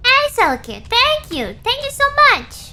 Worms speechbanks
Revenge.wav